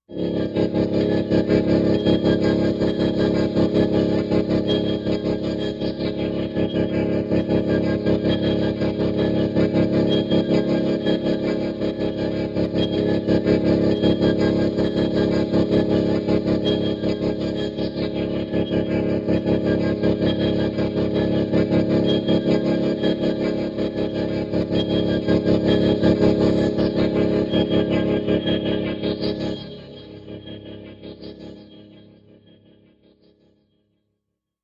Pulsing
Daring Duster, Machine, Electric Buzz, Movement, High, Ring